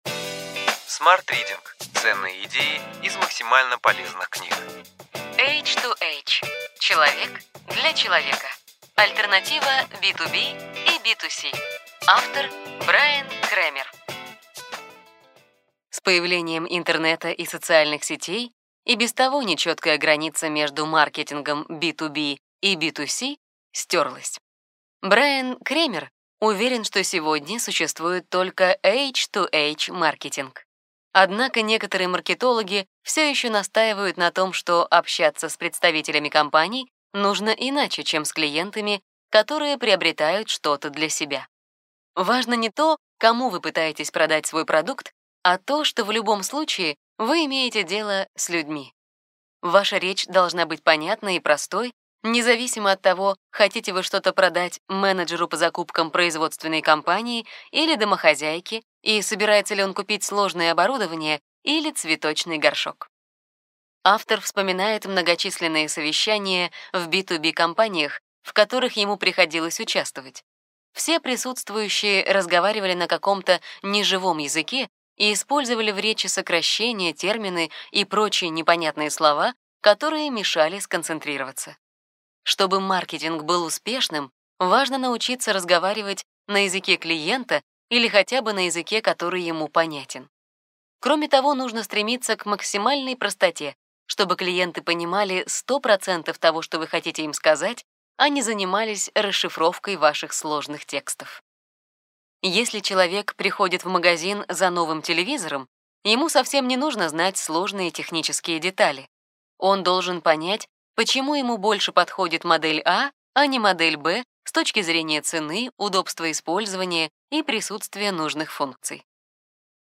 Аудиокнига Ключевые идеи книги: H2H: человек для человека. Альтернатива B2B и B2C.